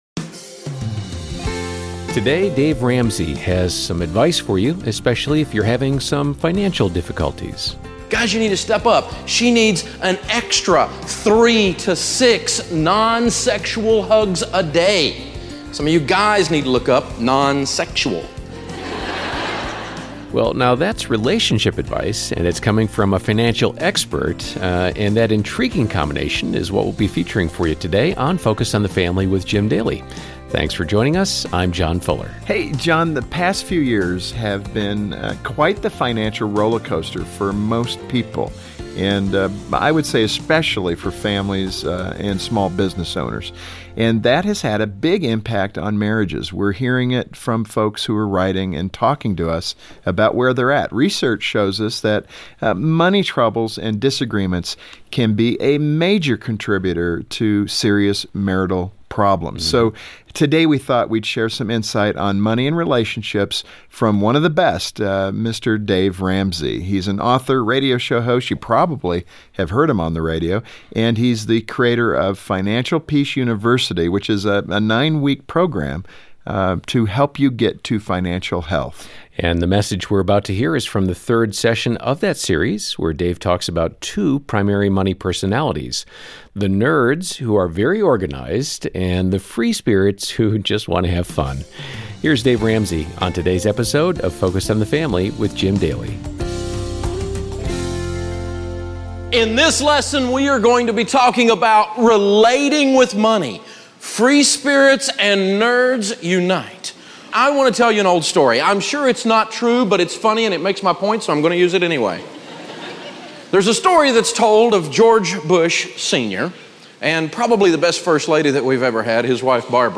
In this upbeat workshop, Dave Ramsey reminds couples that if they can agree on their spending, they will be more united in pursuing their goals and dreams together. He reminds parents of the importance of educating their children in the arts of spending, saving, and giving, and offers encouragement to singles as well.